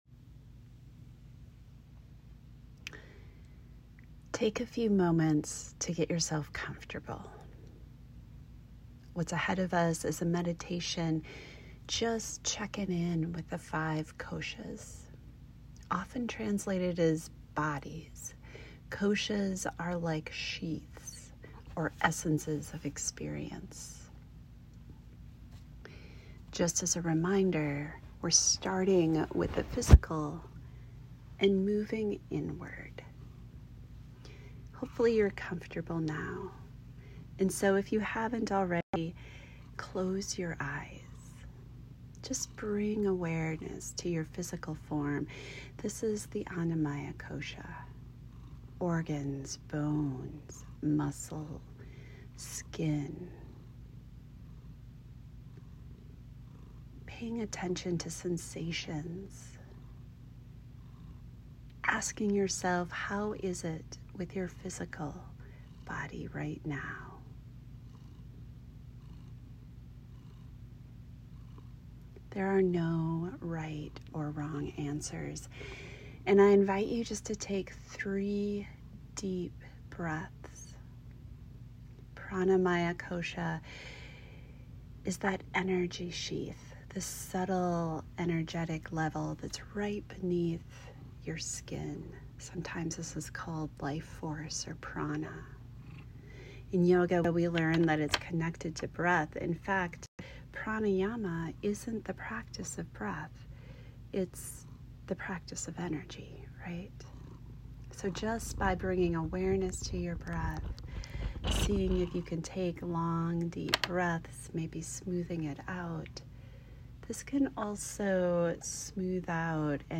Five Koshas Meditation